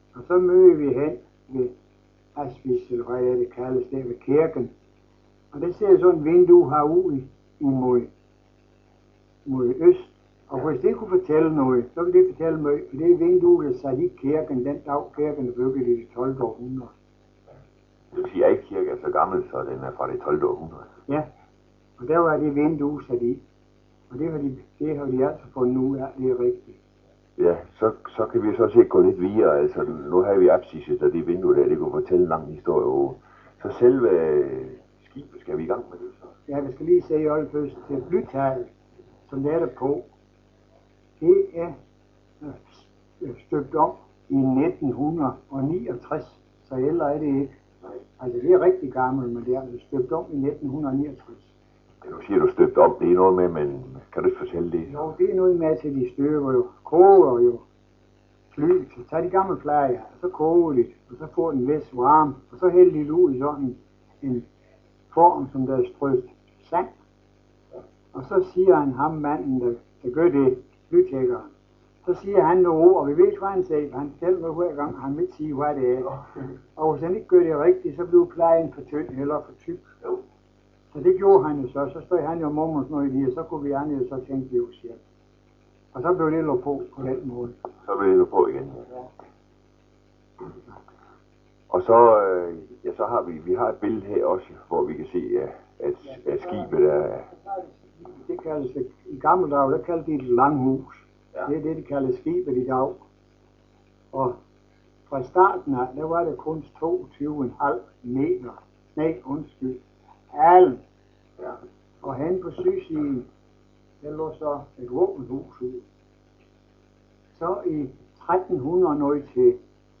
fortælle på ægte Jydsk.